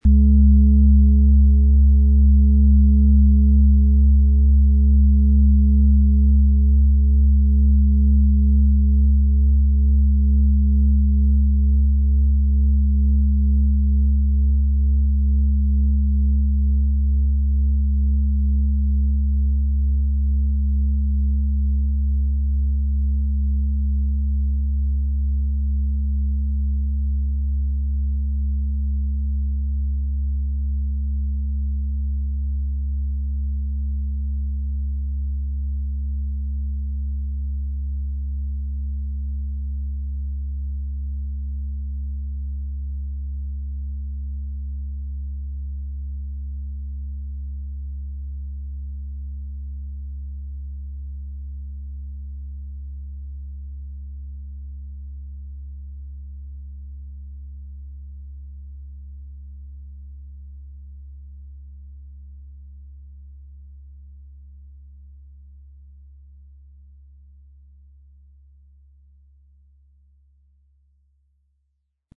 XXXL-Planeten-Fussschale - entdecke mit Uranus die Freiheit - Durchmesser Ø 46,2 cm, 9,75 kg, bis ca. Schuhgröße 42, mit Klöppel.
Den passenden Schlegel erhalten Sie kostenfrei mitgeliefert, der Schlägel lässt die Schale voll und wohltuend erklingen.
PlanetentonUranus & Uranus (Höchster Ton)
MaterialBronze